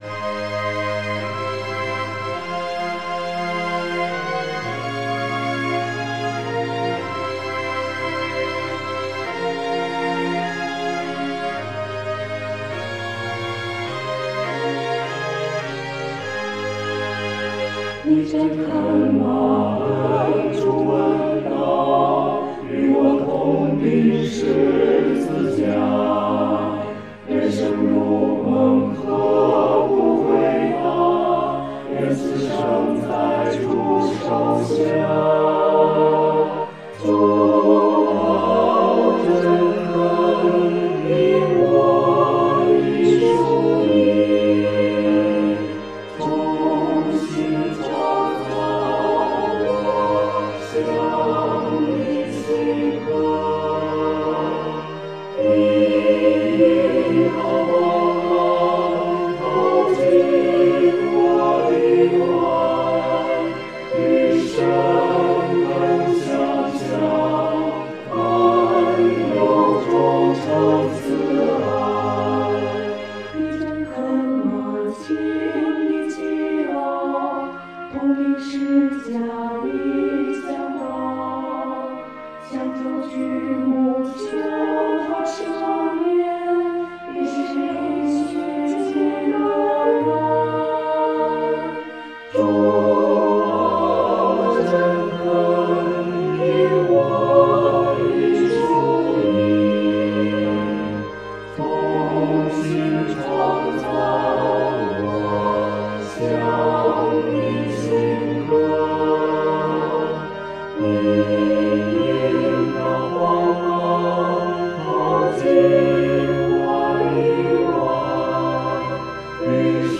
四声五线谱       2.